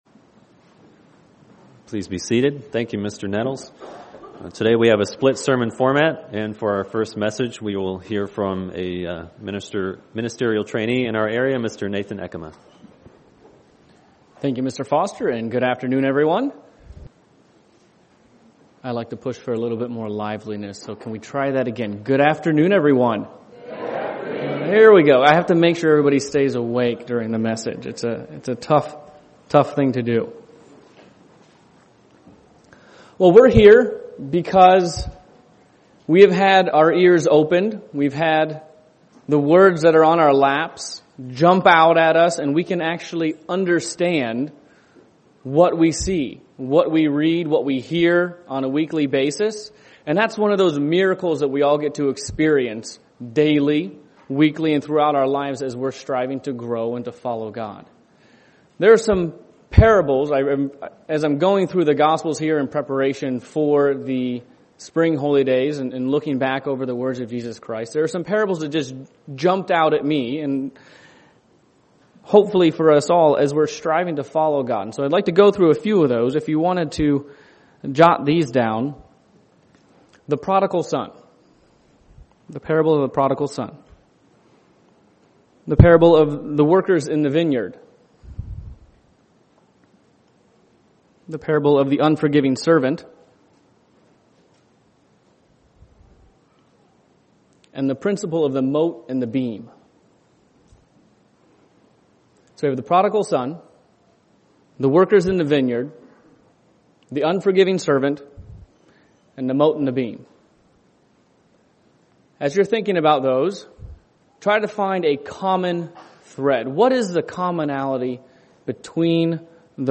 In this message, we'll explore some of the parables of Jesus Christ and see what we are admonished to avoid and overcome UCG Sermon Studying the bible?